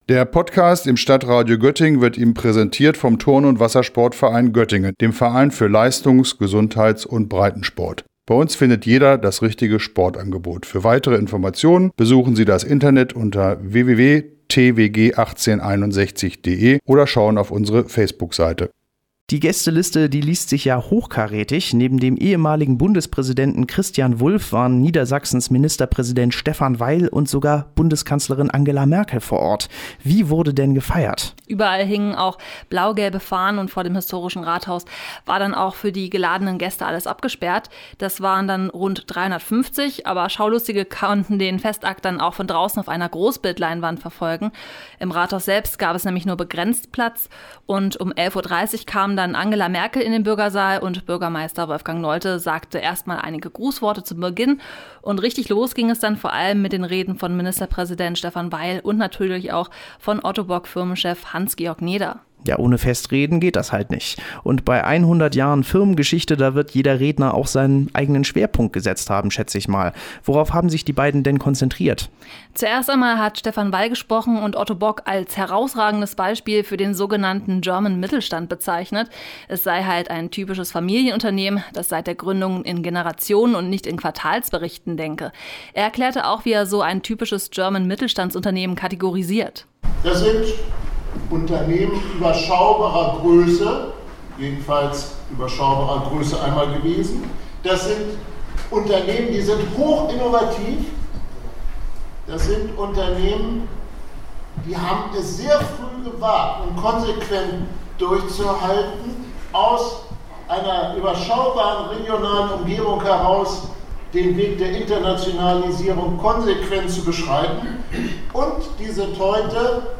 Beiträge > 100 Jahre Ottobock – Merkel und Weil zu Gast bei Festakt in Duderstadt - StadtRadio Göttingen